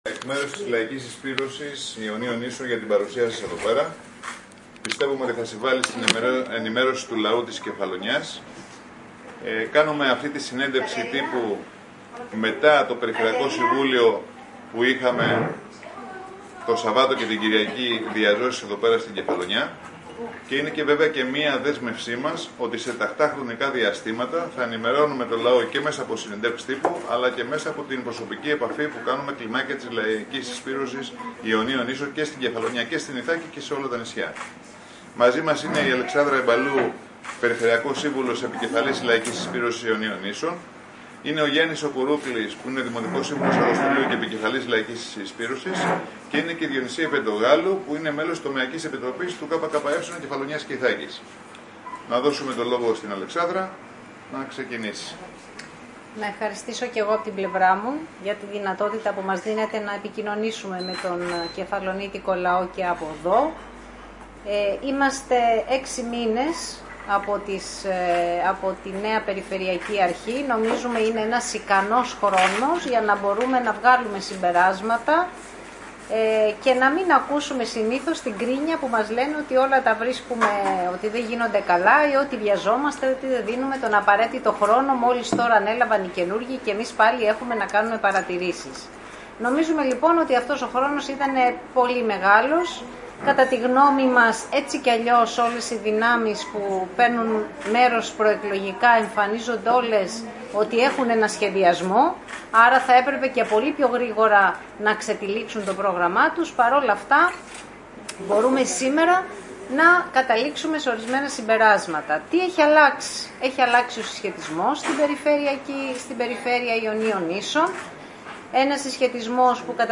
Συνέντευξη τύπου παραχώρησε η επικεφαλής της Λαϊκής Συσπείρωσης της Περιφέρειας Ιονίων Νήσων Αλεξάνδρου Μπαλού μαζί και ο Περιφερειακός Σύμβουλος Νίκος Γκισγκίνης.